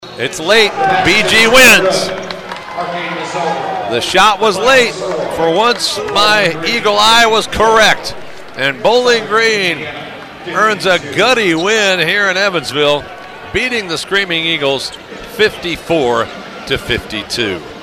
RADIO CALLS